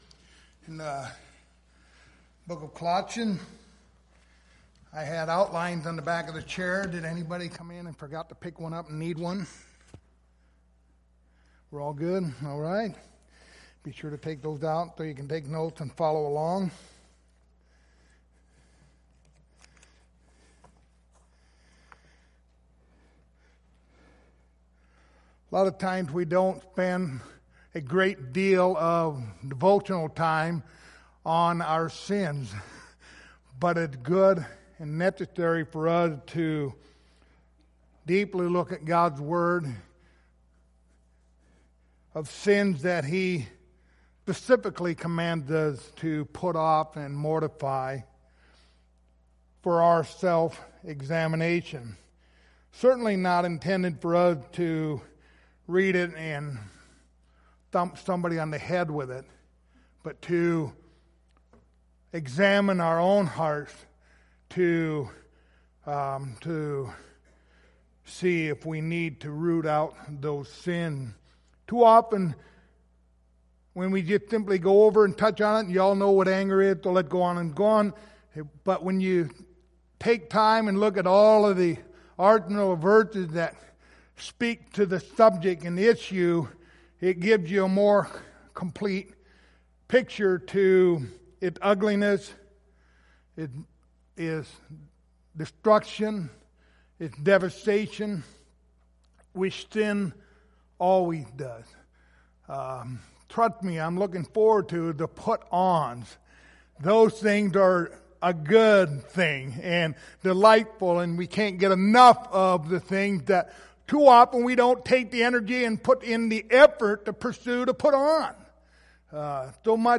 Passage: Colossians 3:8b-9 Service Type: Sunday Evening